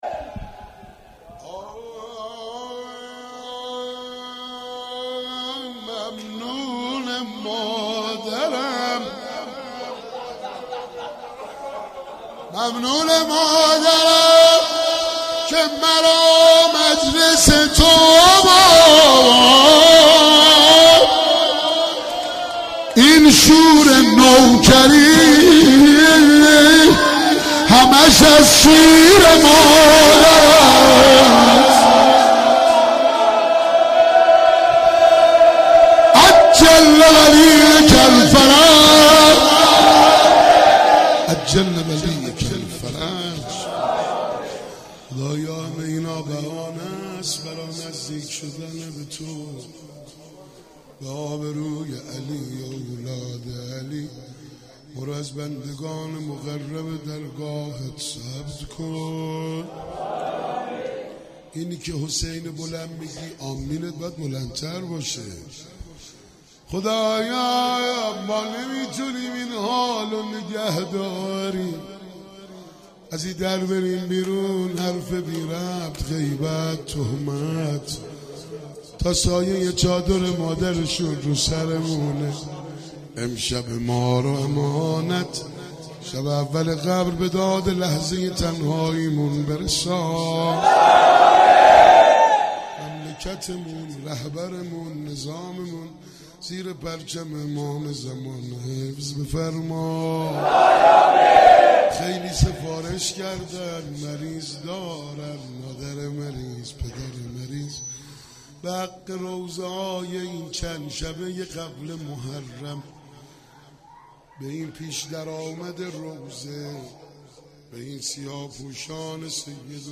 روضه .خمسه